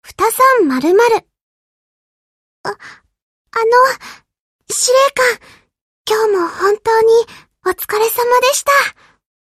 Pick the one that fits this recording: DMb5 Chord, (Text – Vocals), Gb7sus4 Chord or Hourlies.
Hourlies